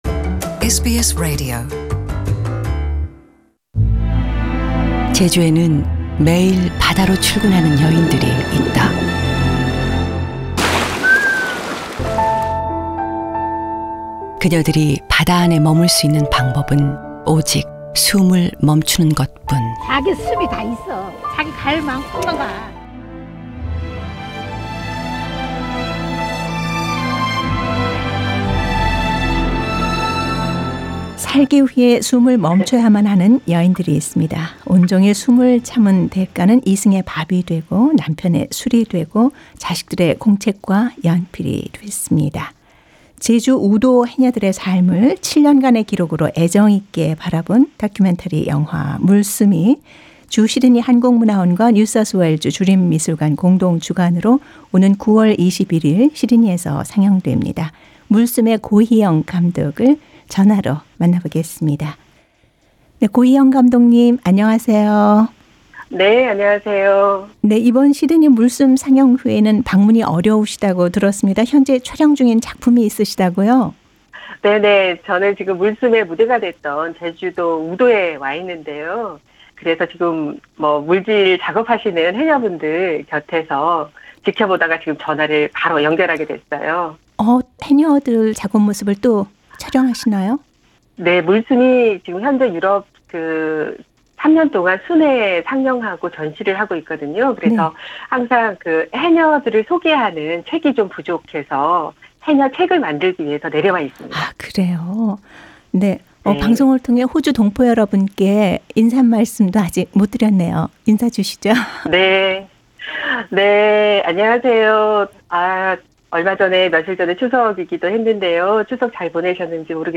[Cinema Interview] 'Breathing underwater' depicts the underwater life of Korean women divers